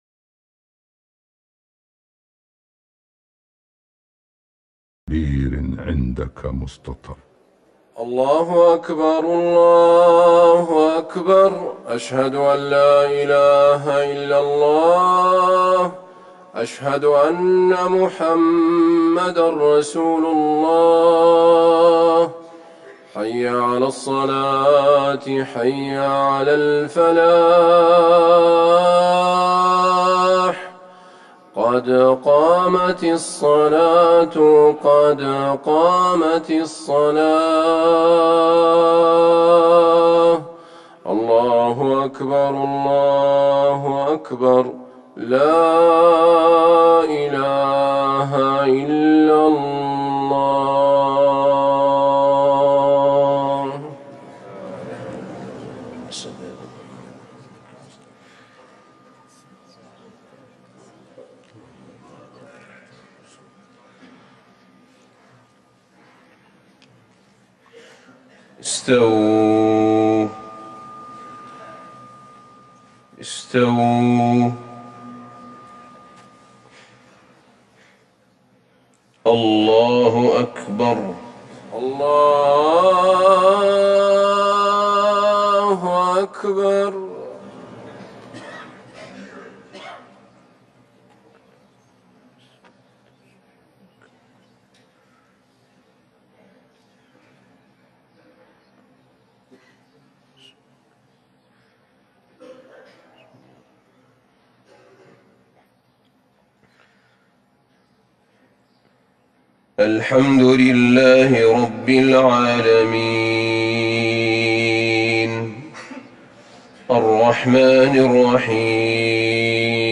صلاة الفجر ١٤ جمادي الاخره ١٤٤١هـ سورة المعارج والقيامة Fajr prayer 8-2-2020 from Surah Al-Maarij and Alqiama > 1441 🕌 > الفروض - تلاوات الحرمين